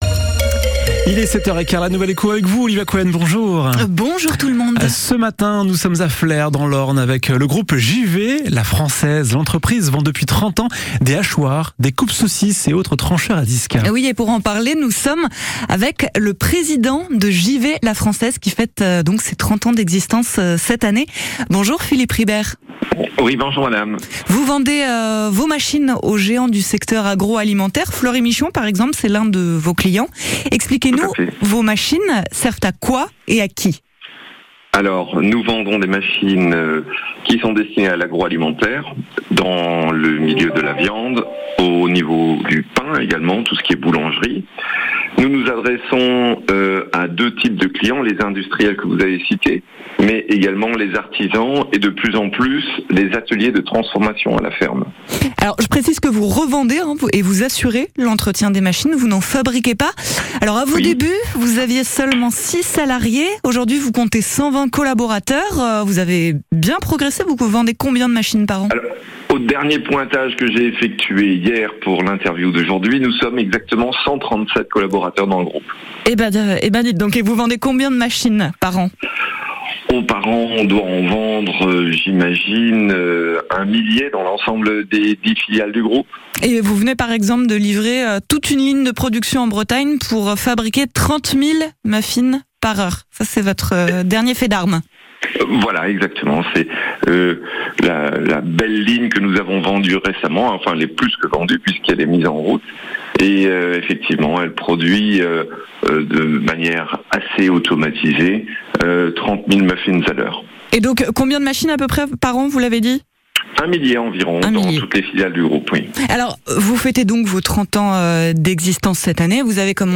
Interview France Bleu Normandie, chronique « La nouvelle éco »